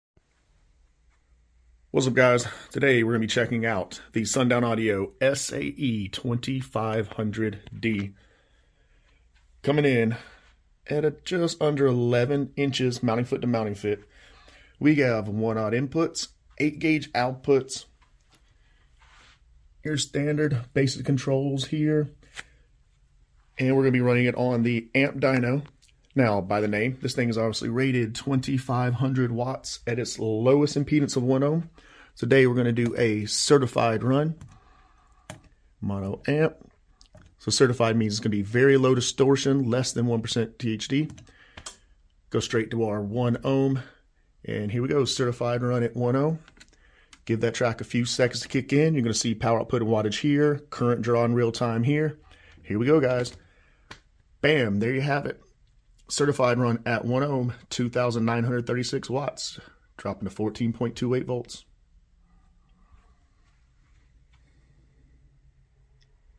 Sundown Audio SAE-2500D certified 1ohm amp dyno test. car audio bass sound subwoofer amplifier videos